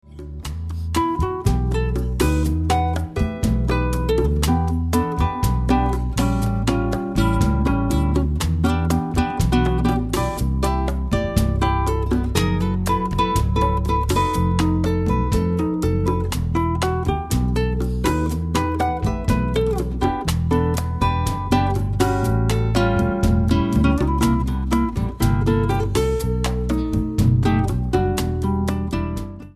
guitar
bass
drums and percussions